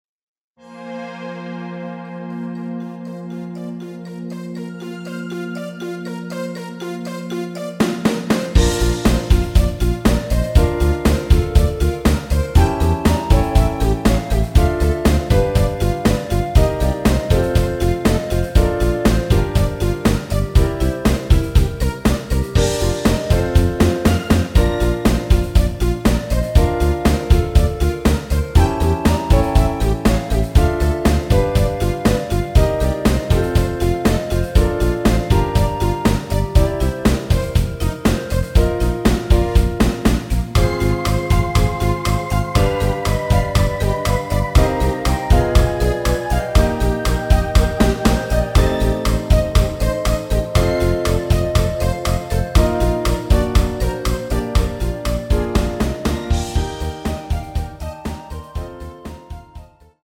엔딩이 페이드 아웃이라 가사의 마지막 까지후 엔딩을 만들어 놓았습니다.!
여성분이 부르실수 있는 키로 제작 하였습니다.(미리듣기 참조)
Bb
앞부분30초, 뒷부분30초씩 편집해서 올려 드리고 있습니다.
중간에 음이 끈어지고 다시 나오는 이유는